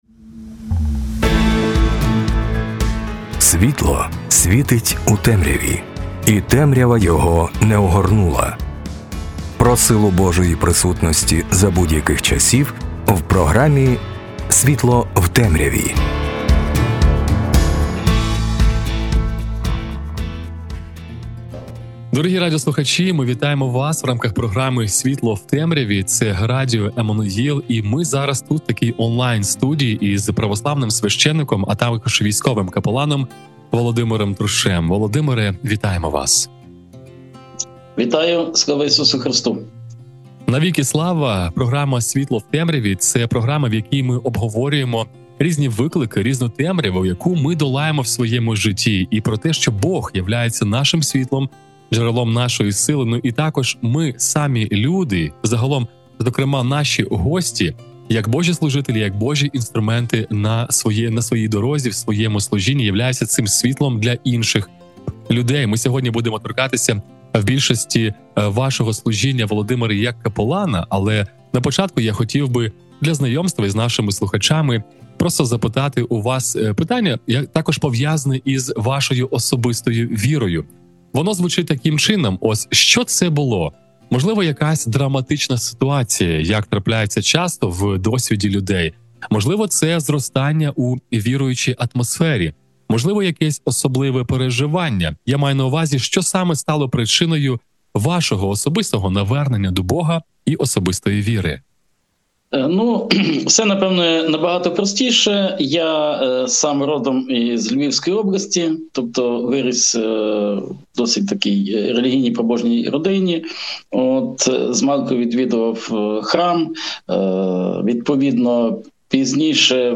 Спілкування зі священником та капеланом про суть капеланського служіння, про виклики даної праці, про правильне відношення до військових та їх справи, про людяність та джерело сили та спокою в Богові.